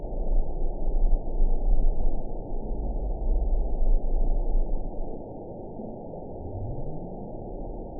event 922636 date 02/13/25 time 23:40:26 GMT (2 months, 2 weeks ago) score 9.25 location TSS-AB10 detected by nrw target species NRW annotations +NRW Spectrogram: Frequency (kHz) vs. Time (s) audio not available .wav